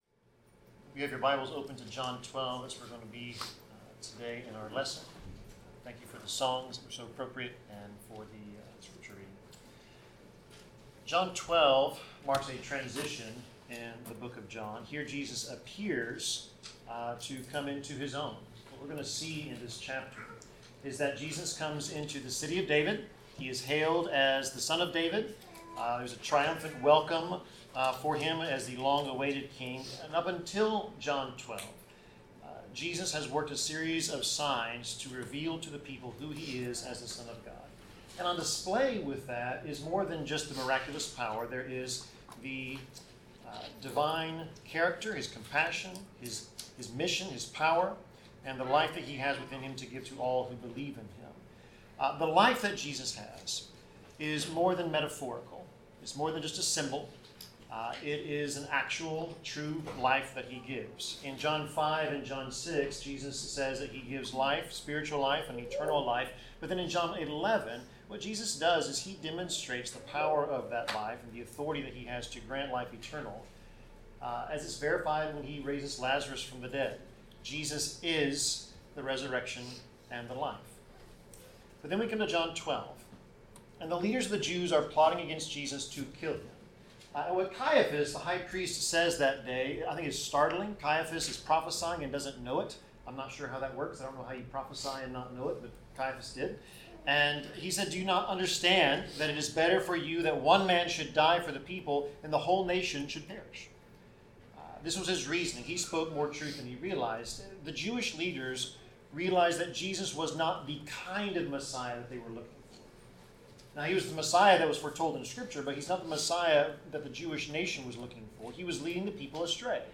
Passage: John 12 Service Type: Sermon